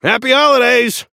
Shopkeeper voice line - Happy holidays!